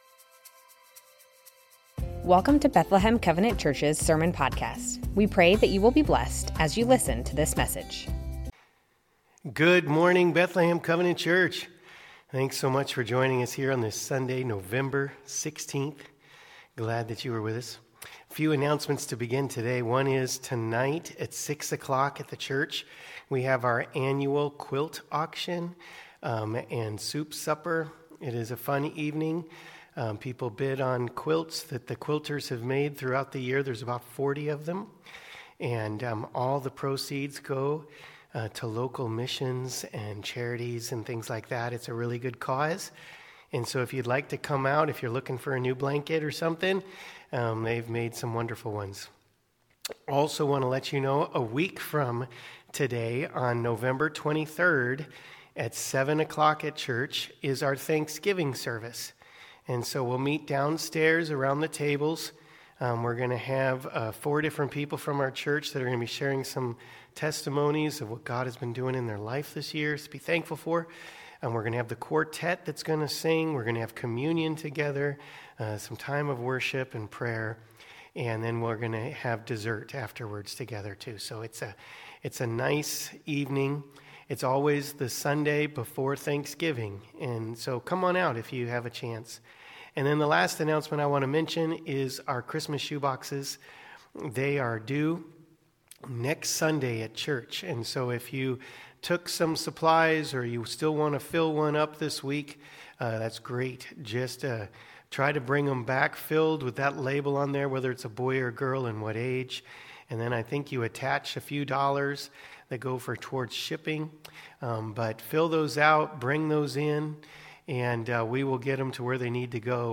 Bethlehem Covenant Church Sermons The Names of God - Yeshua Nov 16 2025 | 00:34:47 1x 00:00 / 00:34:47 Subscribe Share Spotify RSS Feed Share Link Embed